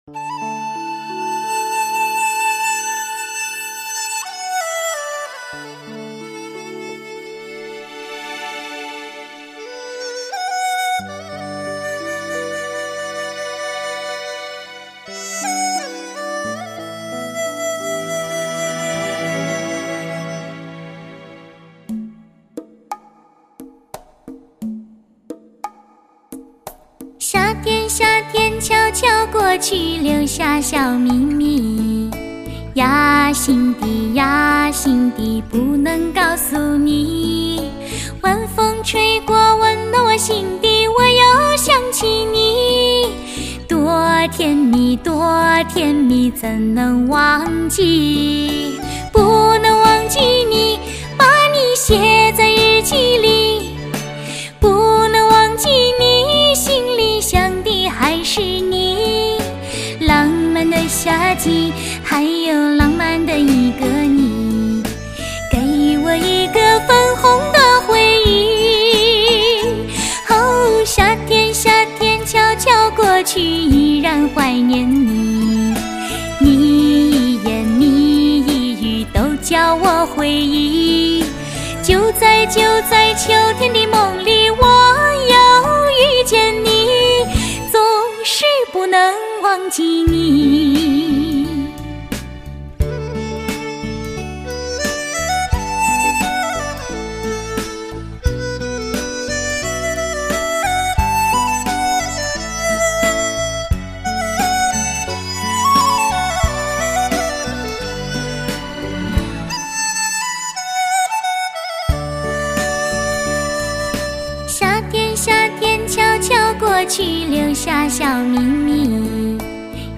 类型: 天籁人声
清水芙蓉般的声线魅力，甜美的歌声将听者带入梦幻般恬淡的乐境中。
曲带点江南小调风格，散发着浓浓的清新和中国风气息。